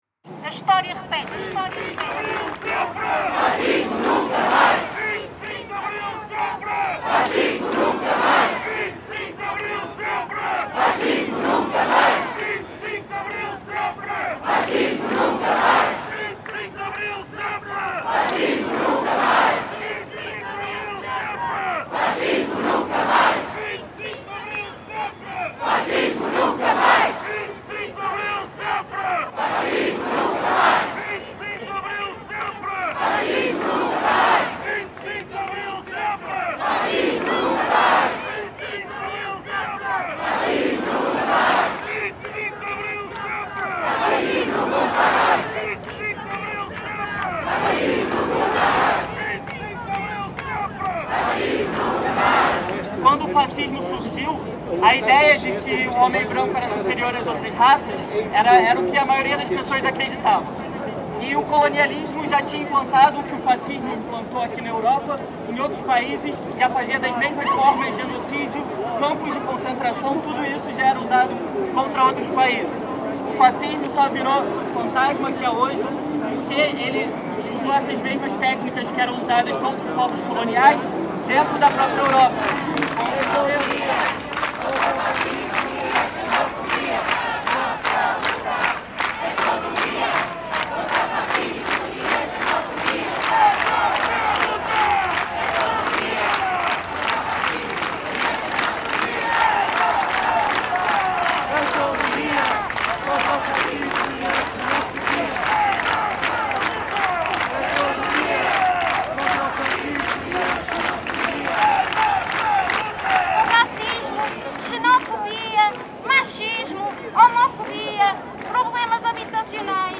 Antifa protest in Portugal, 2020